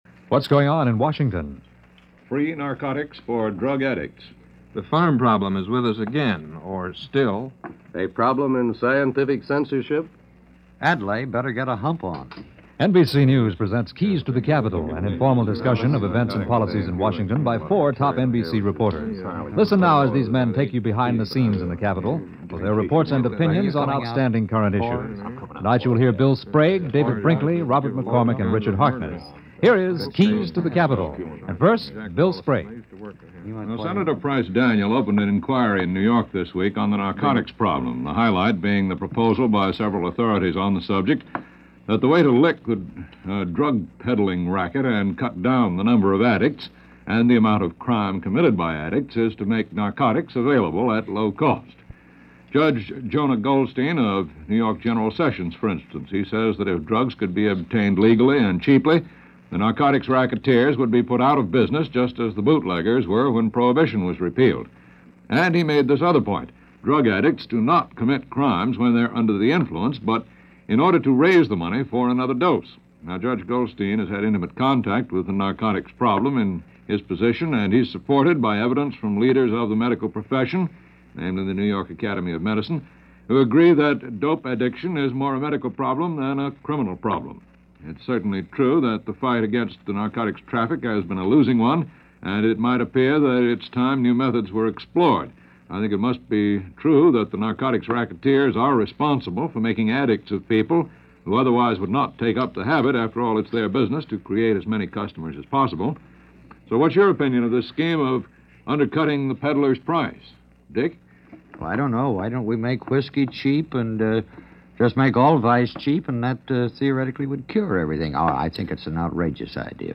NBC-Keys-To-The-Capitol-1955.mp3